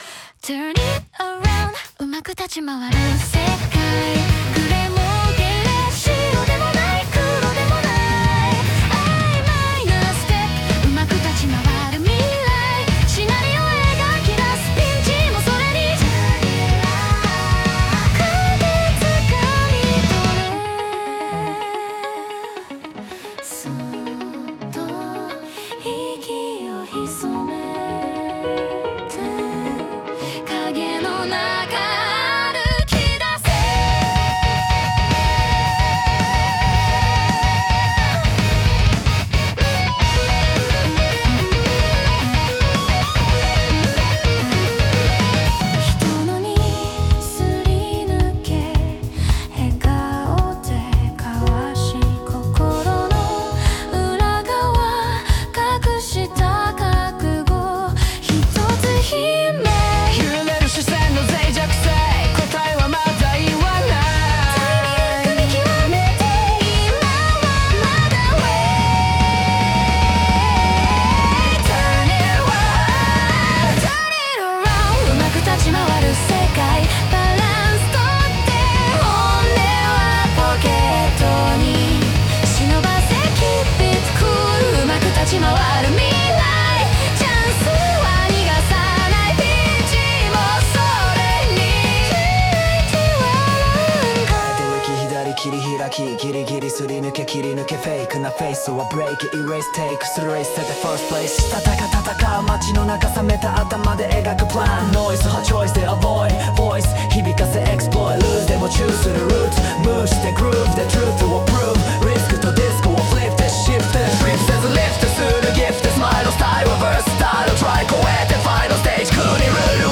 女性ボーカル
イメージ：J-ROCK,女性ボーカル,男性ラップ,かっこいい,複雑,シューゲイザー